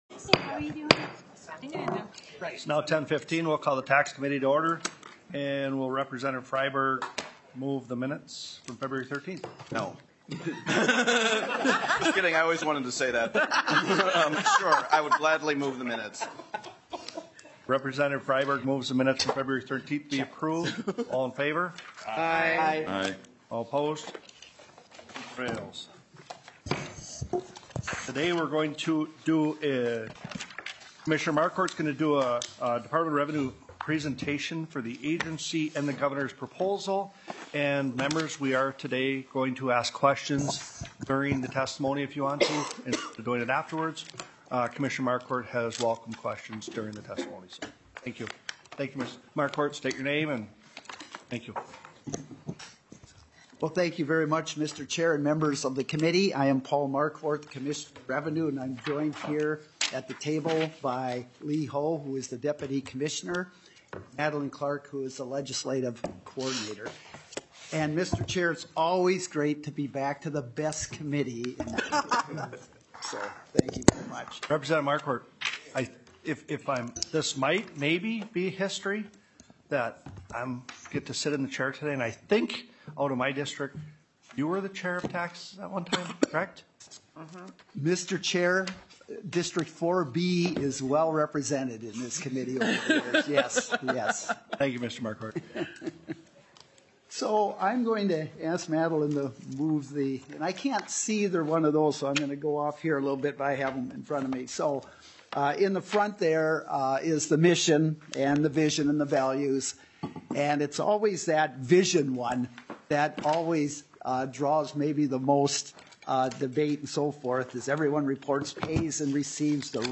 00:28 - Department of Revenue agency presentation. 39:26 - Department of Revenue presentation on the 2025 governor's budget recommendations.